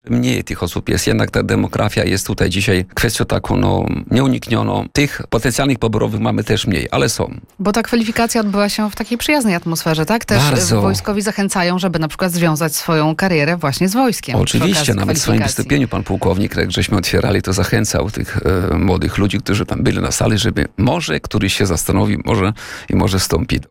W tym roku wezwanie otrzymało ponad 250 osób, ale to i tak mniej niż w latach ubiegłych – mówił na antenie Radia Nadzieja starosta Tadeusz Klama.